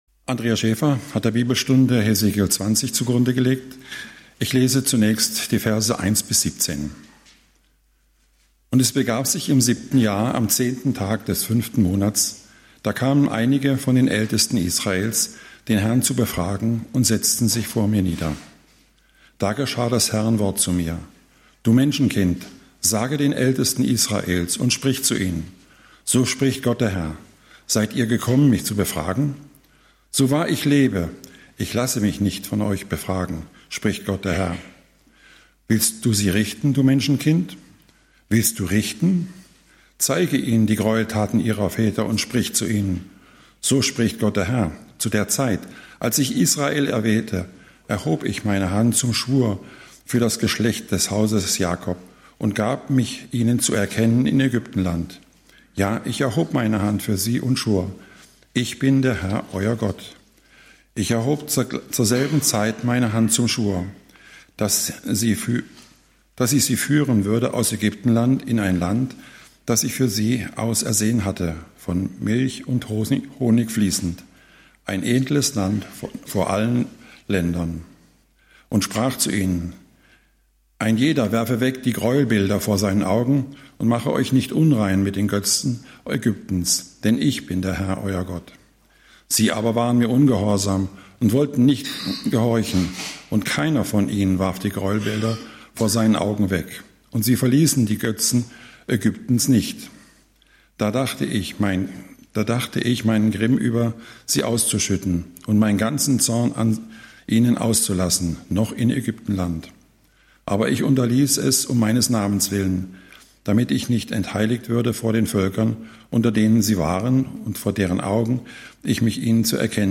Bibelstunde Redner